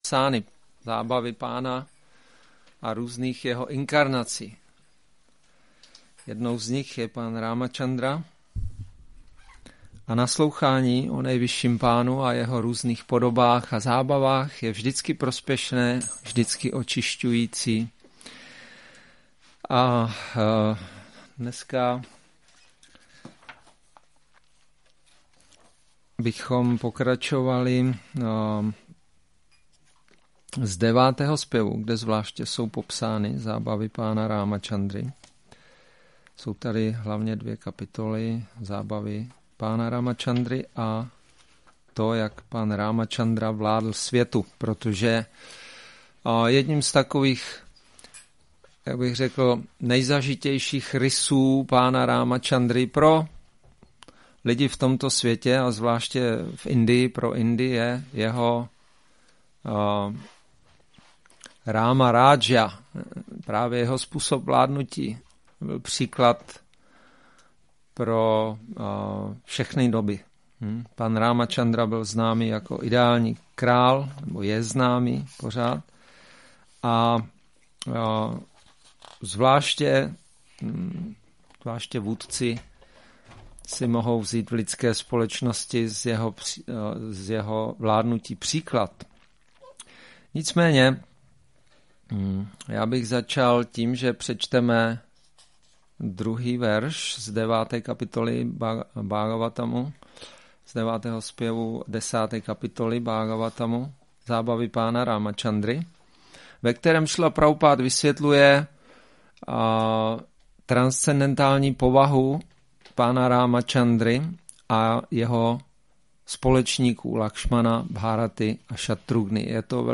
Šrí Šrí Nitái Navadvípačandra mandir
Přednáška Rama navami